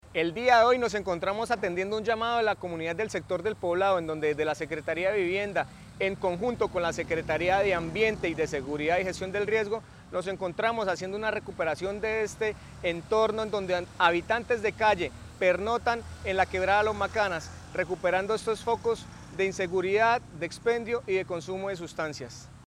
Fernando Serrano. Secretario de Vivienda.mp3